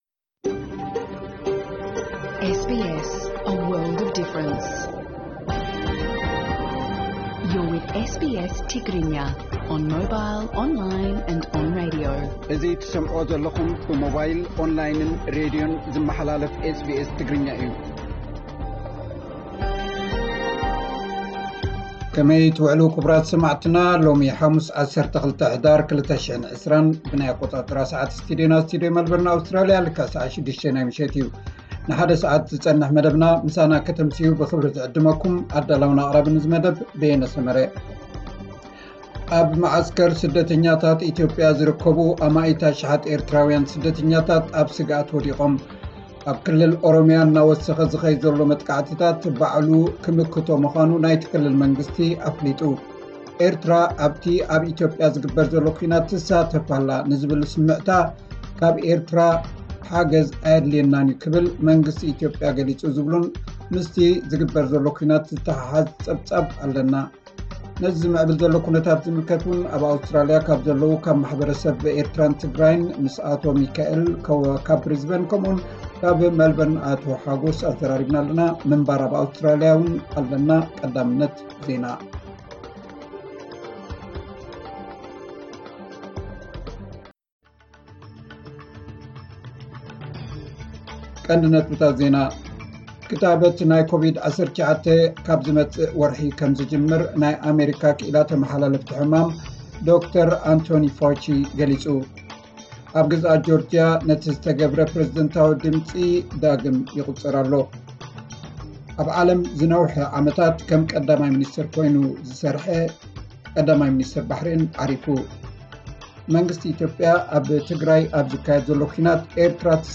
ዕለታዊ ዜና፥ ሓሙስ 12 ሕዳር 2020